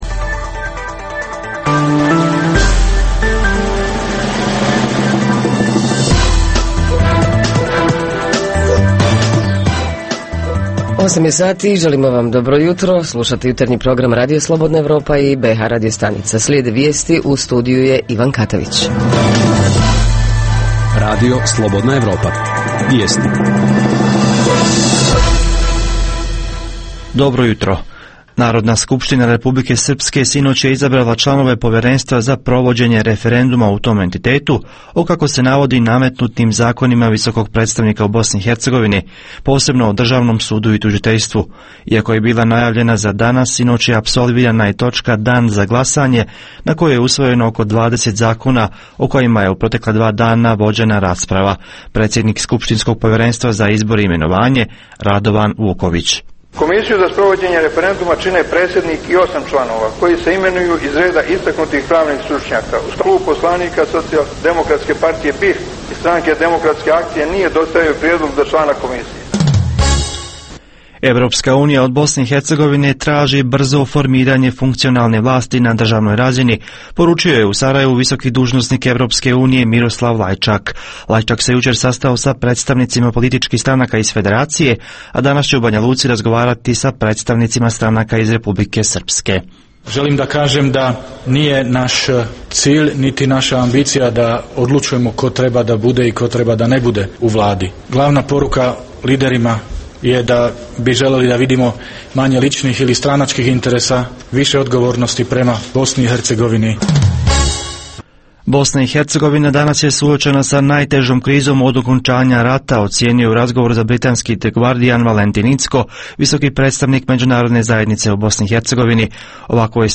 Na Svjetski dan plesa dobro će biti da saznamo vole li naši sugrađani plesati, bave li se neki od njih plesom i šta im ples znači. Reporteri iz cijele BiH javljaju o najaktuelnijim događajima u njihovim sredinama.
Redovni sadržaji jutarnjeg programa za BiH su i vijesti i muzika.